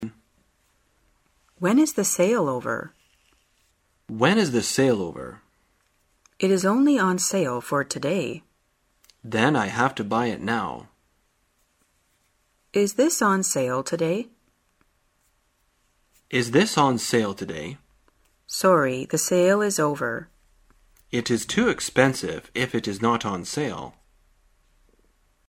在线英语听力室生活口语天天说 第159期:怎样谈论促销活动的听力文件下载,《生活口语天天说》栏目将日常生活中最常用到的口语句型进行收集和重点讲解。真人发音配字幕帮助英语爱好者们练习听力并进行口语跟读。